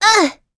Gremory-Vox_Damage_06.wav